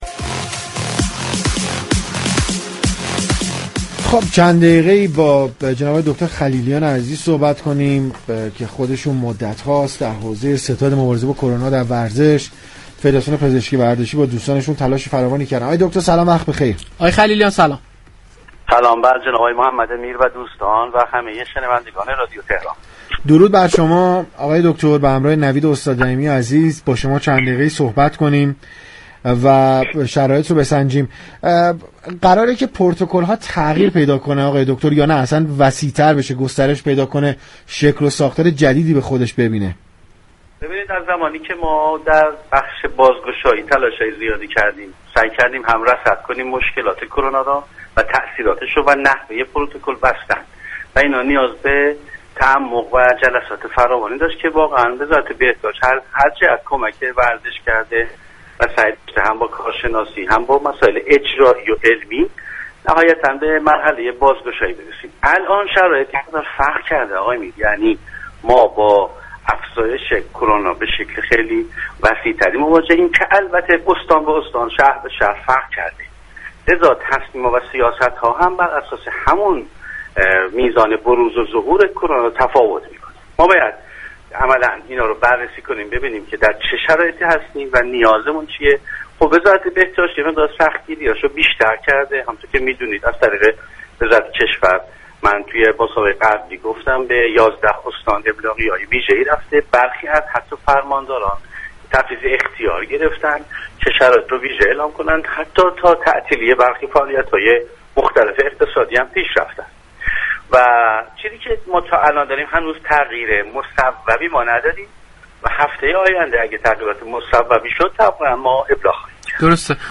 در گفتگو با تهران ورزشی